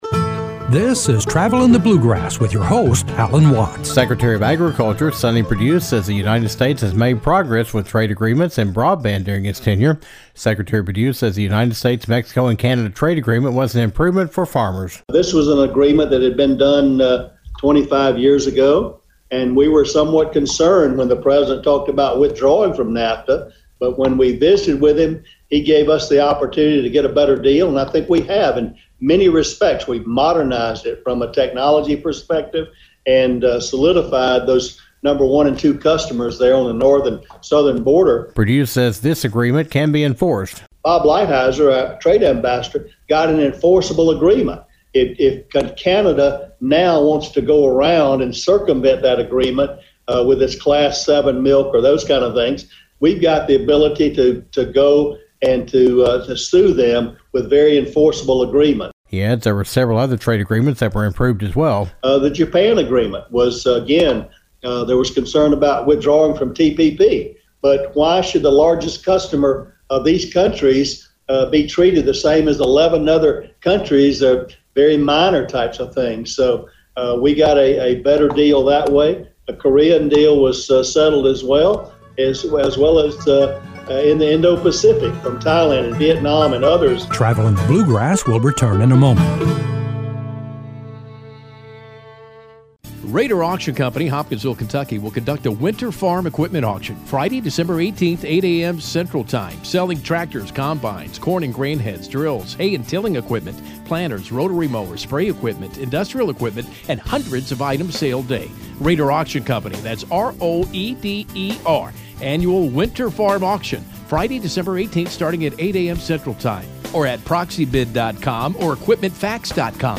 The United States has made progress with the USMCA and other trade agreements around the world.  Secretary of Agriculture Sonny Perdue discusses the USMCA and other trade agreements, and the Secretary provides an update on broadband expansion.